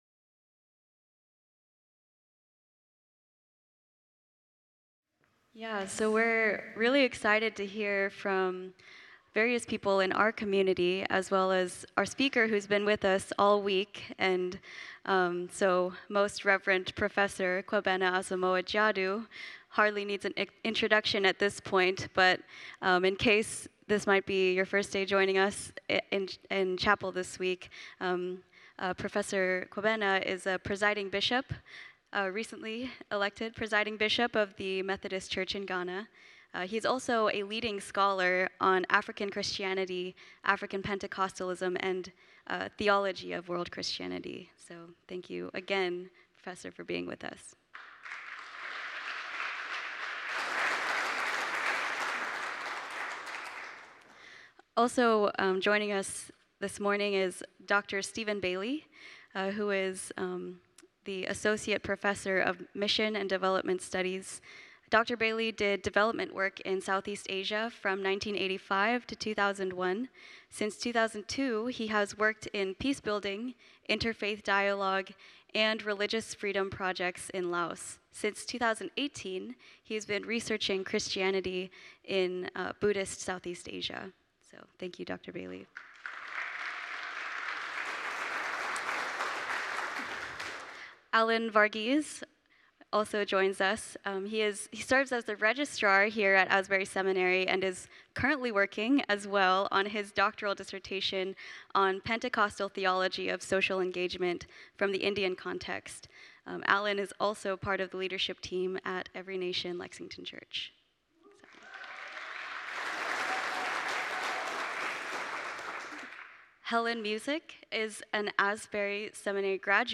Chapel at Estes with Asbury Theological Seminary
The following service took place on Thursday, October 17, 2024.
Panel Discussion